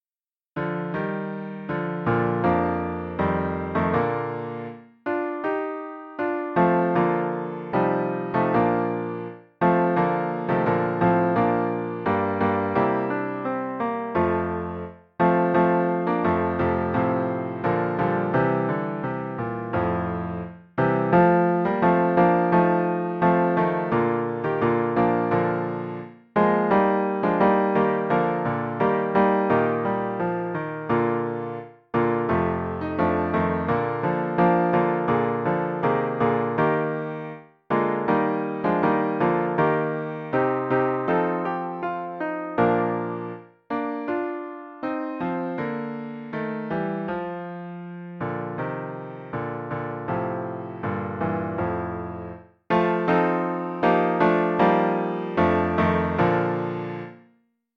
043.b-Fjallkonan (TTBB)